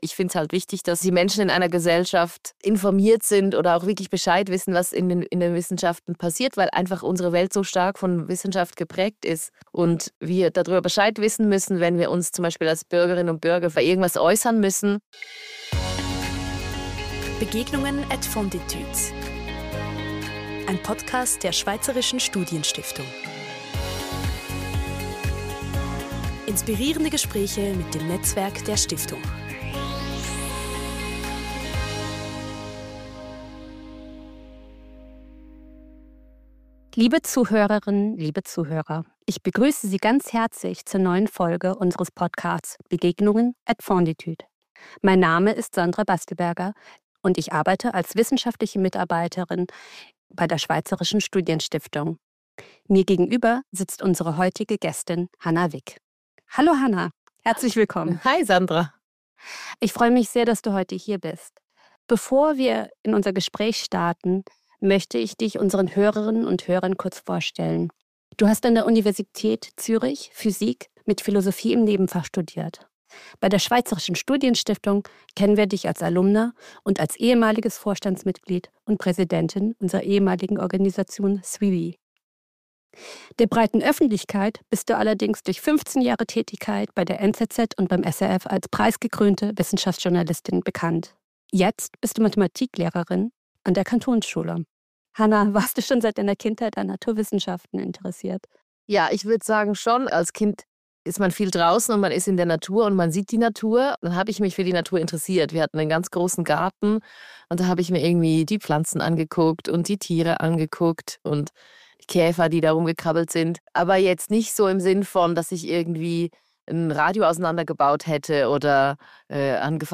Ein Gespräch über die Wichtigkeit von Wissenschaftskommunikation, die Lage des Journalismus und abendfüllende Unterhaltungen in Magliaso.